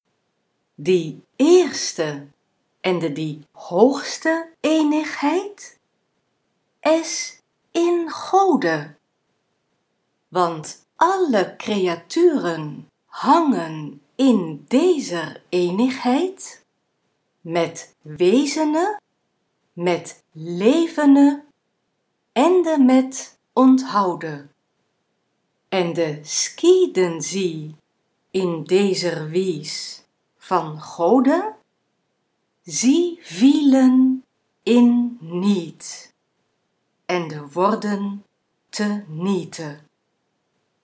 I'll repeat that sentence in Middle Dutch, you can read along in English: